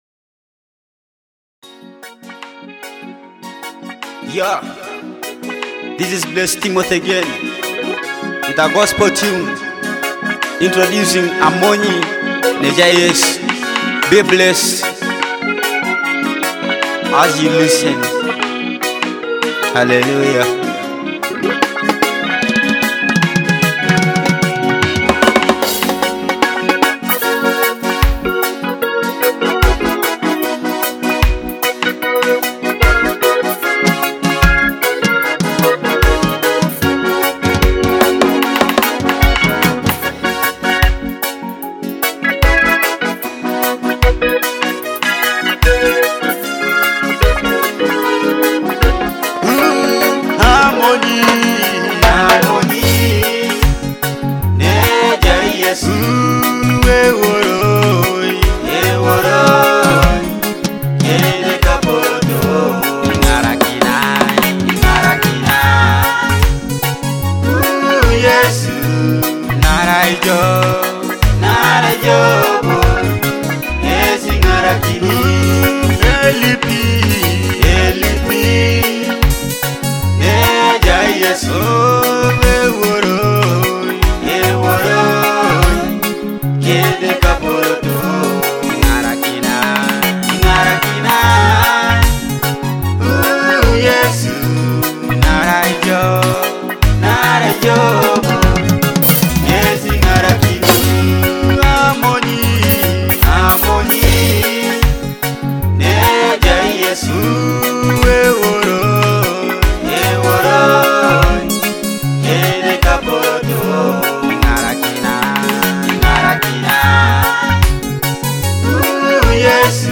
gospel worship songs